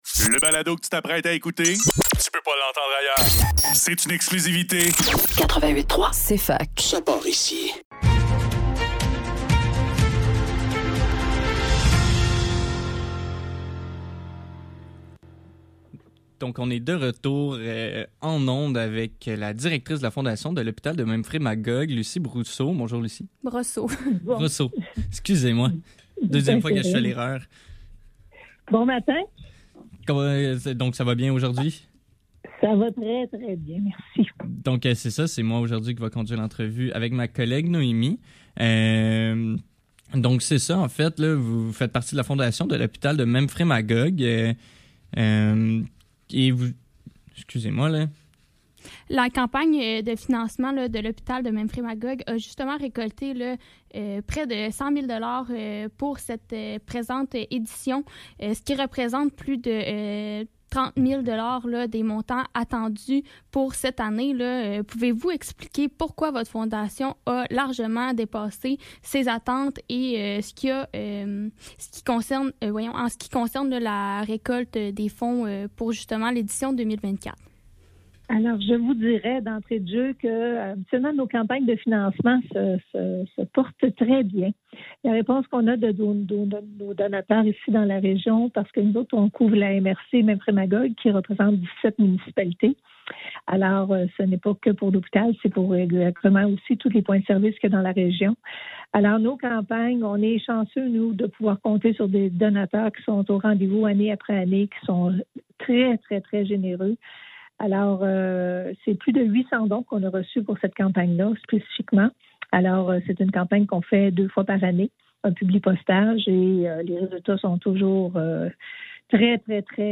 Le NEUF - Entrevue